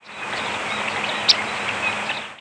Fig.3. Arizona January 26, 2001 (WRE).
"Audubon's" Warbler chip note from perched bird.
"Audubon's" Warbler (D. c. auduboni) gives a higher, huskier, more rising "whik".